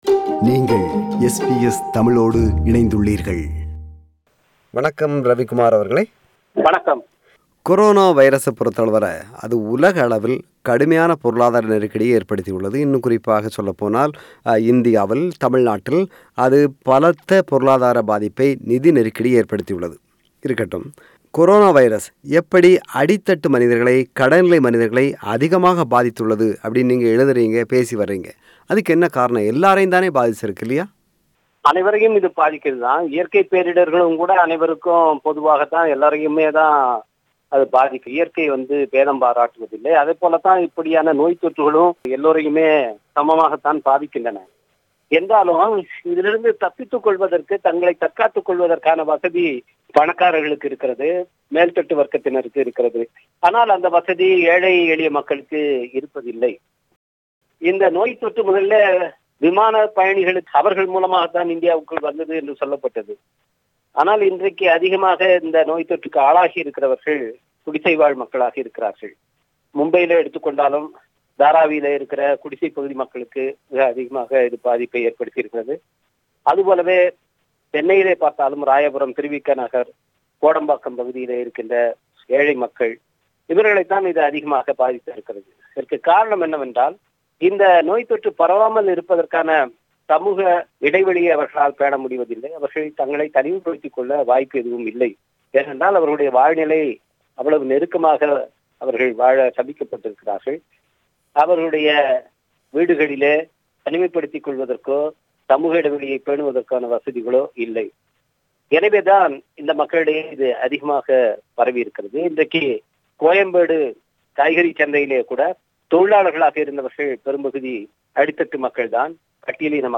தமிழ்நாட்டில் கொரோனா வைரஸ் காரணமாக கடைநிலை மனிதர்கள், ஏழை எளியவர்கள், ஒடுக்கப்படடவர்கள் அதிகமாக பாதிக்கப்பட்டுள்ளனர் என்றும், சாதிய ரீதியாக அவர்கள் அதிக தீண்டாமையை இப்போது எதிர்கொள்கின்றனர் என்றும் கூறுகிறார் இந்திய நாடாளுமன்ற உறுப்பினர் முனைவர் துரை.ரவிக்குமார் அவர்கள்.